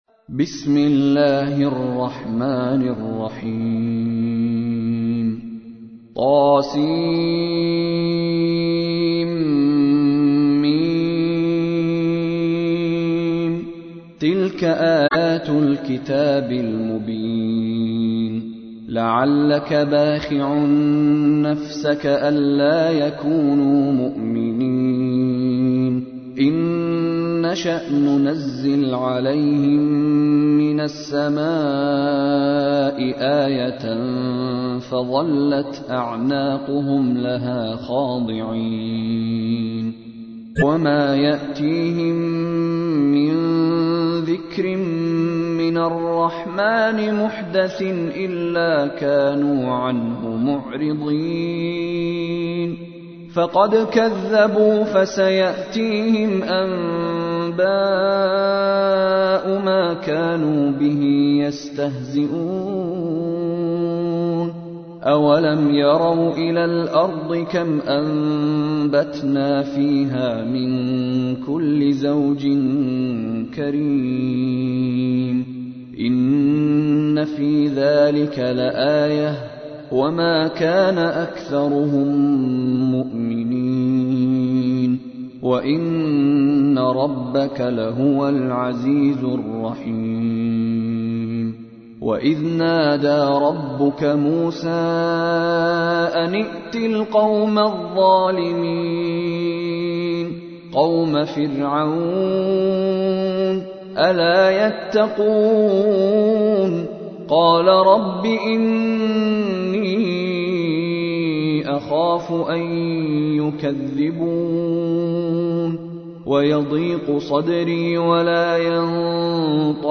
تحميل : 26. سورة الشعراء / القارئ مشاري راشد العفاسي / القرآن الكريم / موقع يا حسين